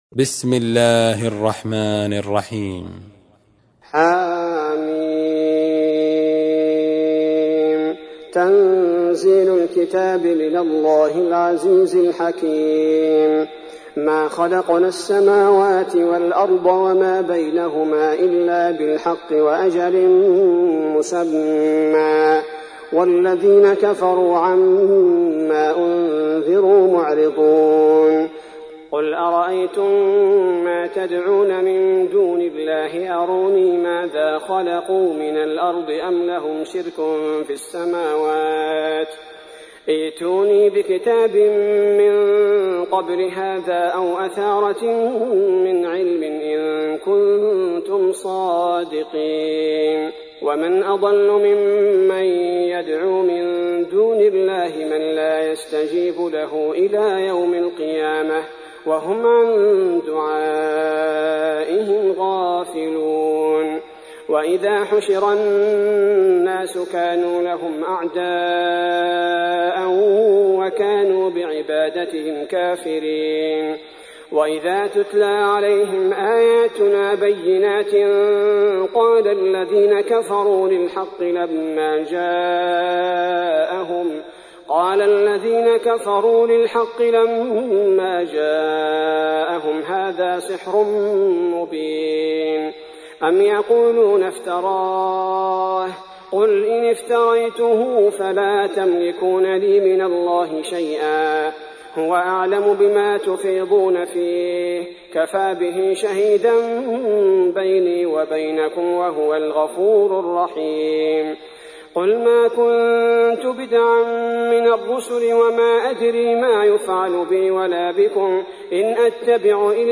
46. سورة الأحقاف / القارئ